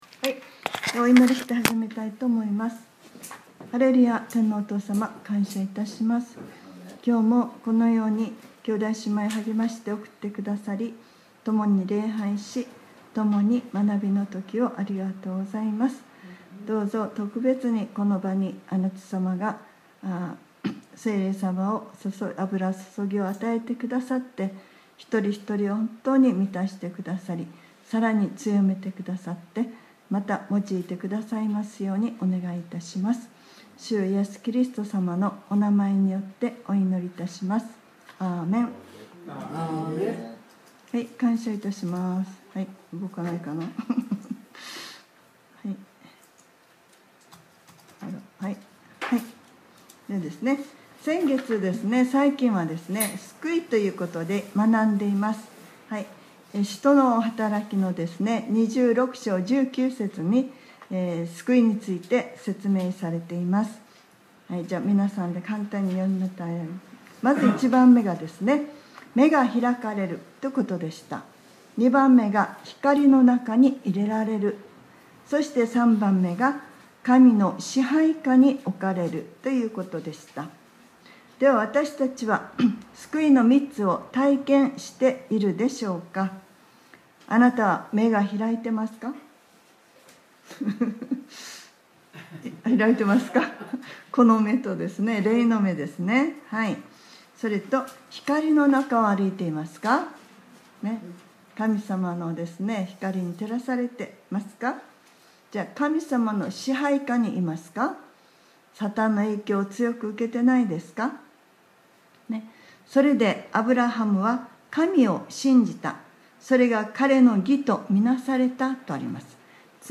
2023年10月15日（日）礼拝説教『 信仰がなければ 』